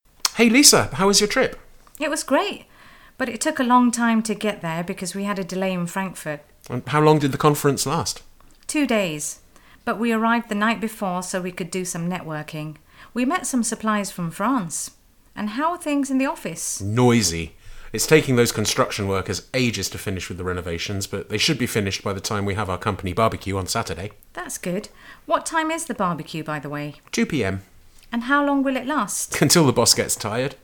Read and listen to this short dialogue between Frank and Lisa during a coffee break